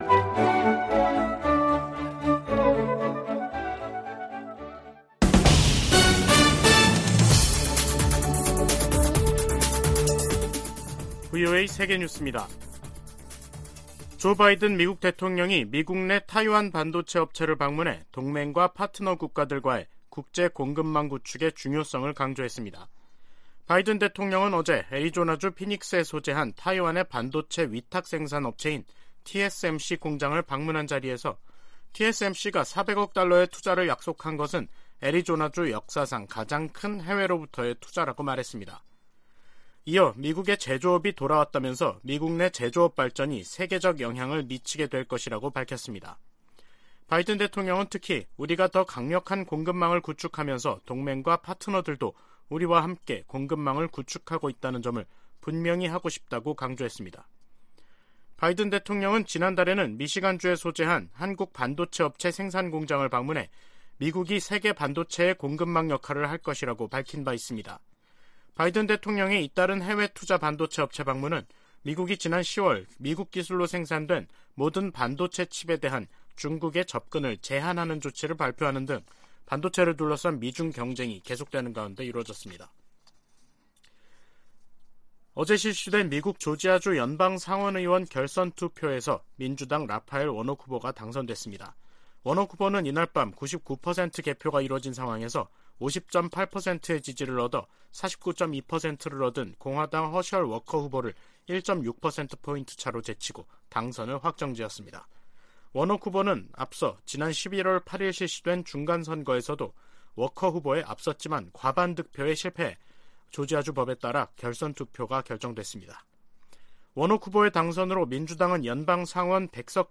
VOA 한국어 간판 뉴스 프로그램 '뉴스 투데이', 2022년 12월 7일 3부 방송입니다. 국무부가 중국과 러시아에 유엔 안보리 대북 결의에 따른 의무를 이행하라고 거듭 촉구했습니다. 미국과 호주가 북한의 불법적인 핵과 탄도미사일 프로그램을 해결하겠다는 의지를 거듭 확인하고 국제사회에 유엔 안보리 결의 준수를 촉구했습니다.